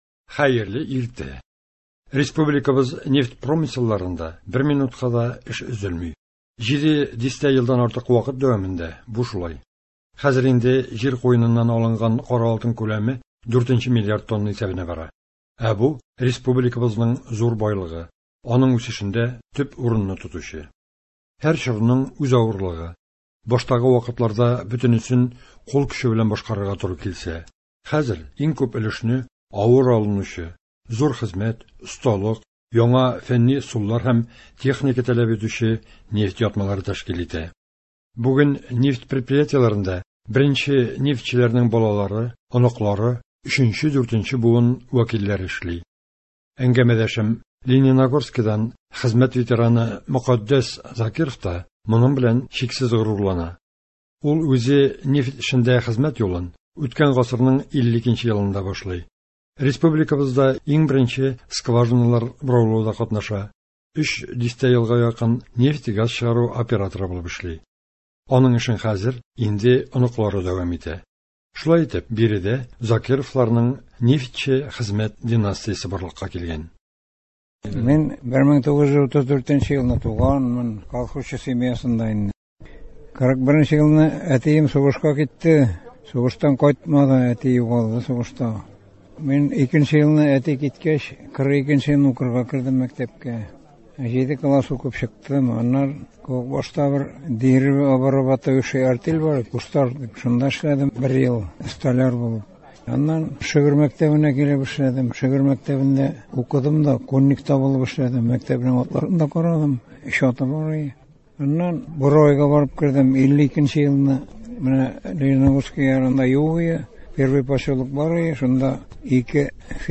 репортажларда кара алтын табучыларның хезмәт һәм ял шартлары, мәдәният һәм социаль өлкәдәге яңалыклар чагылыш таба.